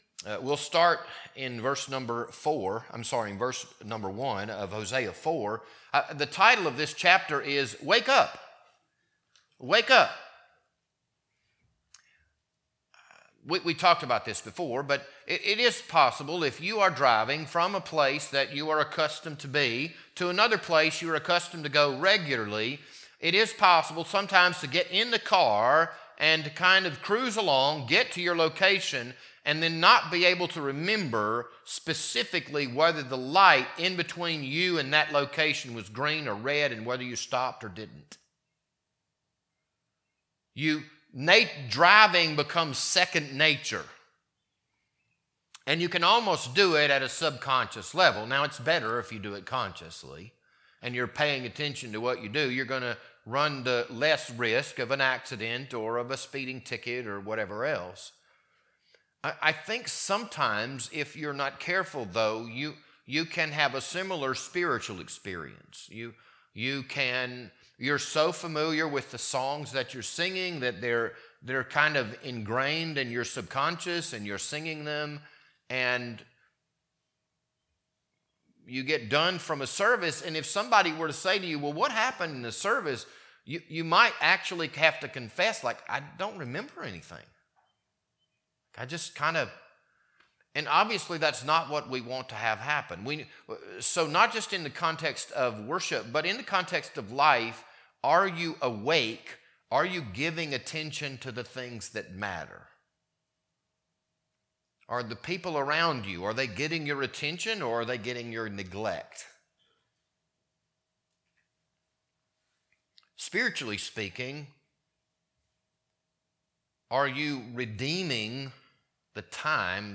This Sunday evening sermon was recorded on March 30th, 2025.